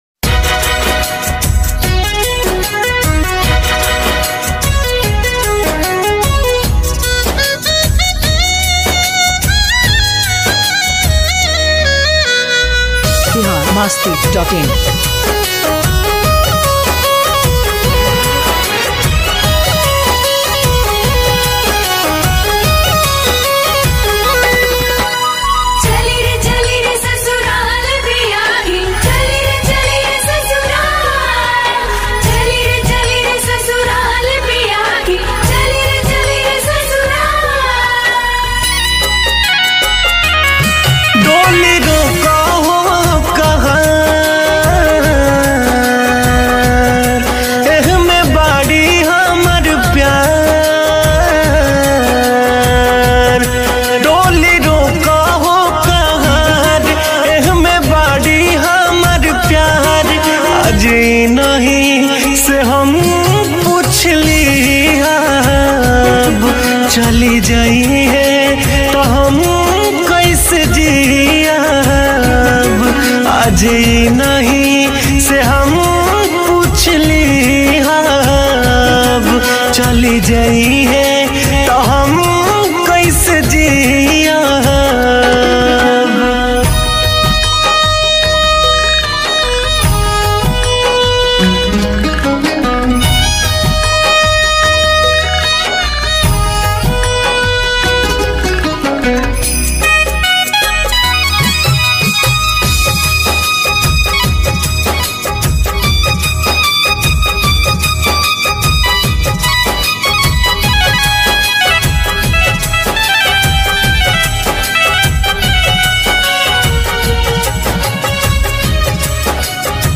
New Sad Song 2021